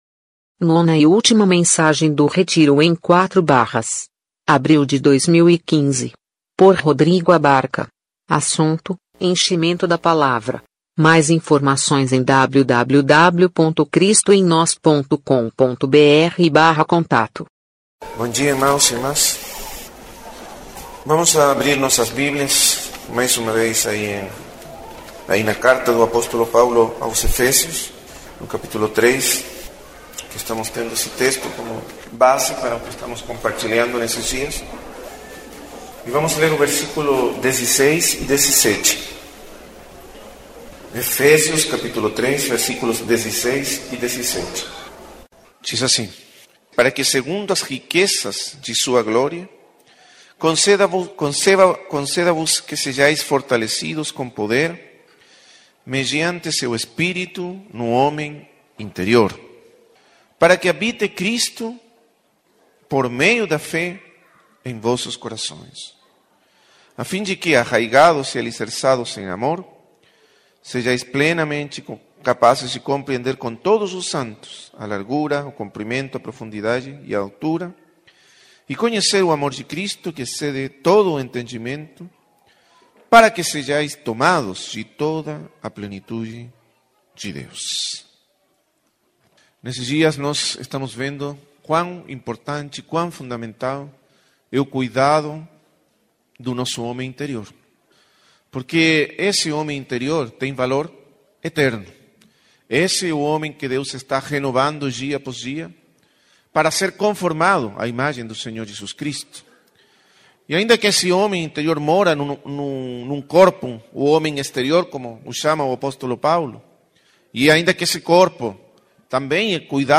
Retiro em Quatro Barras – Abril/2015 | Cristo em Nós